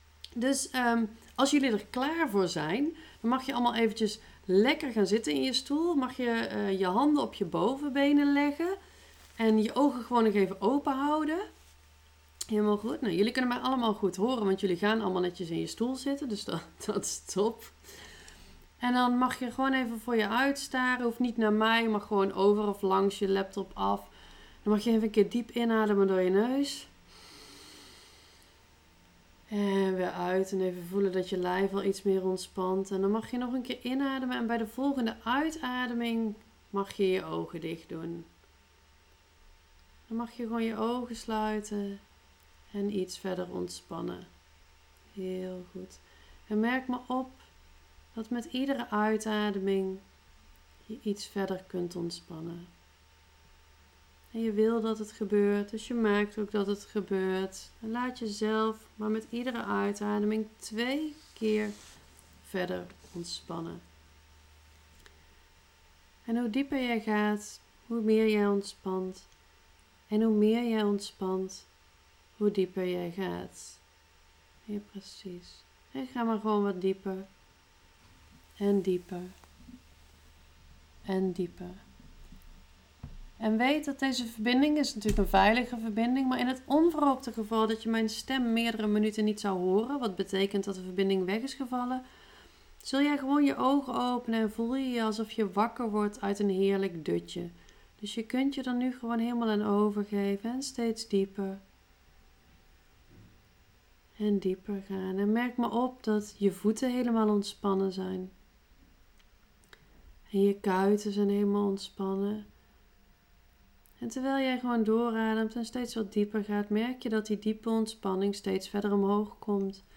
Opname hypnose sessie
Hypnose-succesvolle-ondernemer.mp3